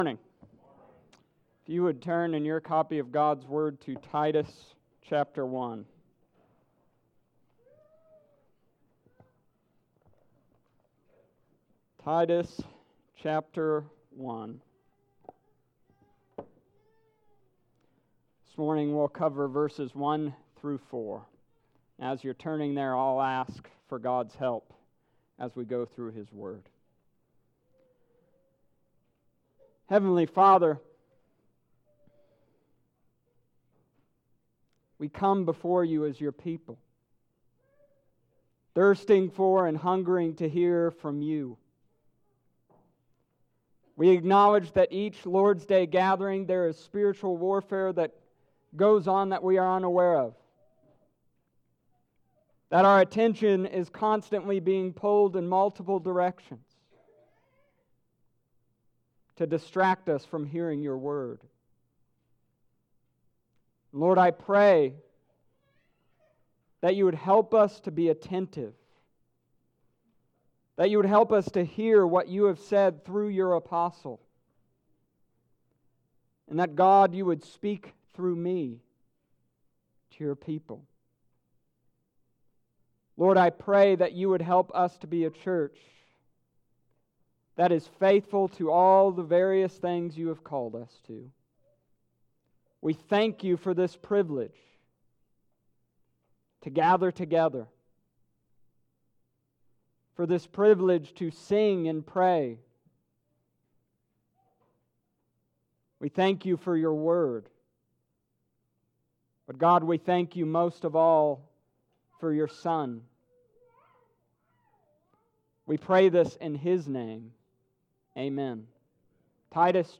Summary of Sermon: This week, we began the book of Titus.